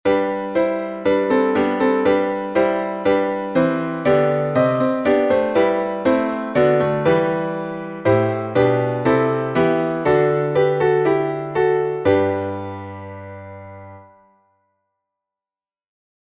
сербский напев